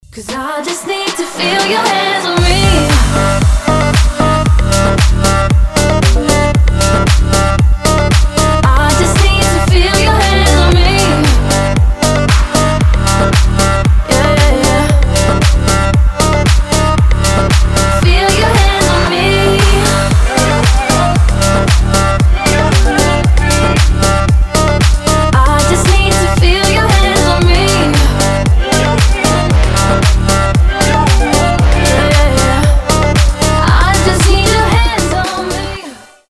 • Качество: 320, Stereo
deep house
восточные мотивы
красивая мелодия
чувственные